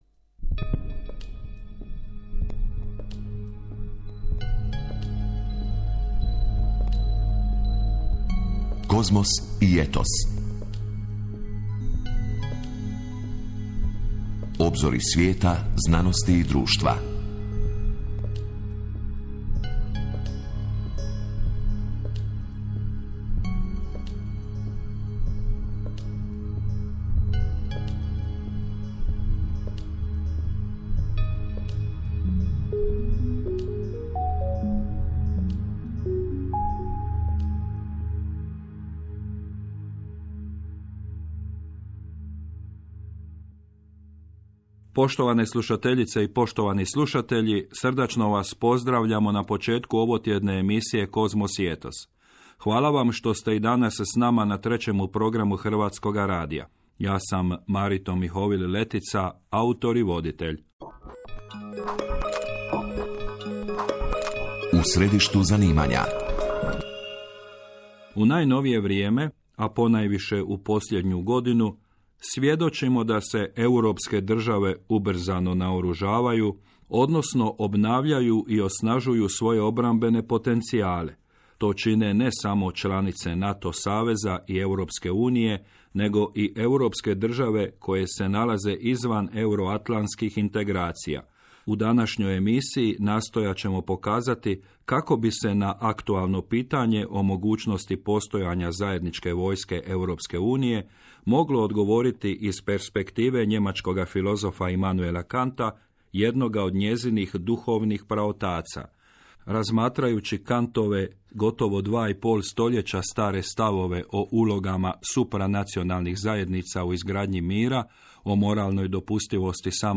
The European Union Army from a Kantian Perspective, interview for the radio programme Kozmos i etos, HRT – HR3